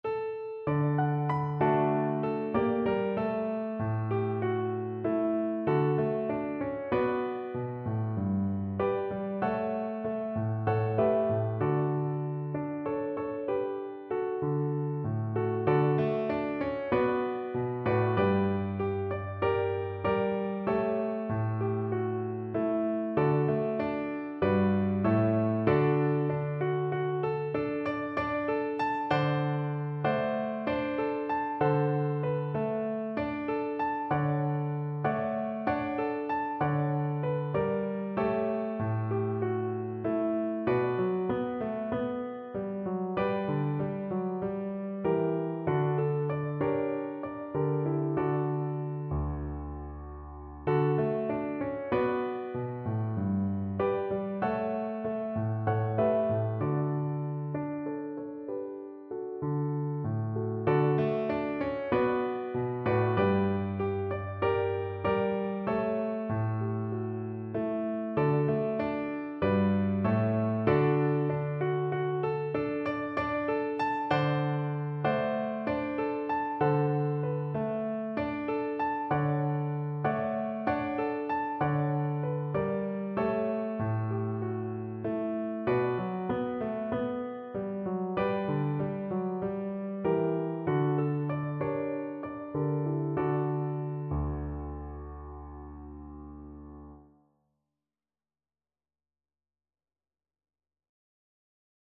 Cello
4/4 (View more 4/4 Music)
D major (Sounding Pitch) (View more D major Music for Cello )
Moderato espressivo =c.96
Traditional (View more Traditional Cello Music)